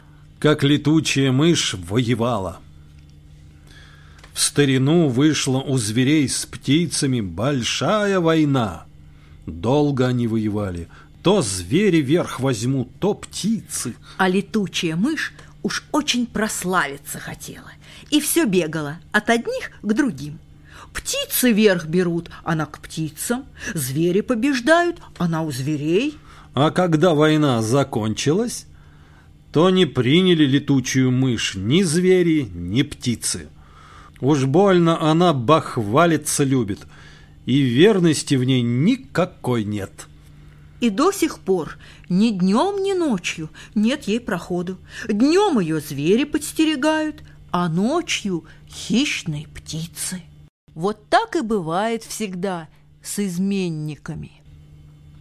Как летучая мышь воевала – латышская аудиосказка